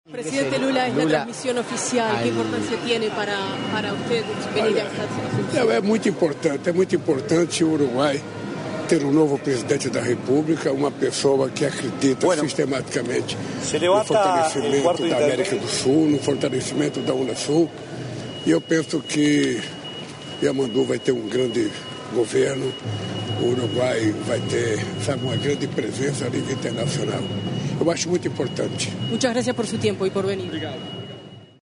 Palabras del presidente de Brasil, Luiz Inácio “Lula” da Silva
Este sábado 1.° de marzo, en oportunidad del traspaso de mando presidencial en Uruguay, se expresó el mandatario de la República Federativa de Brasil,